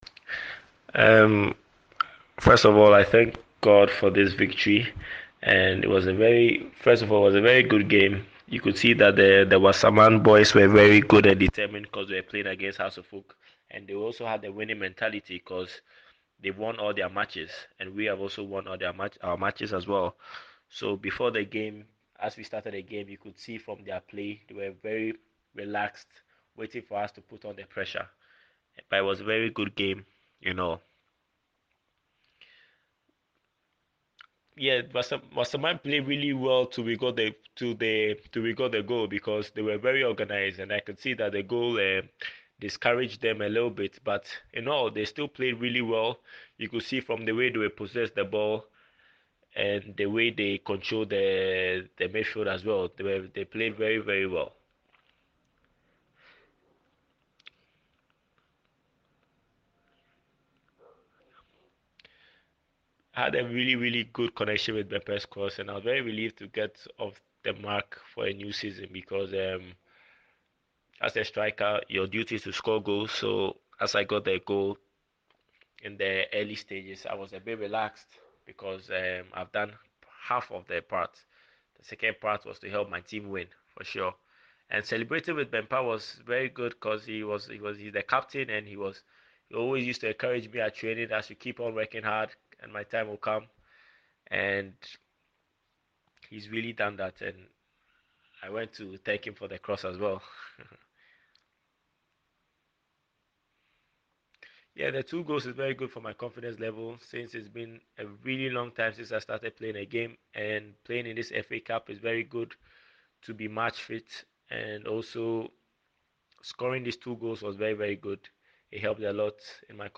Interview by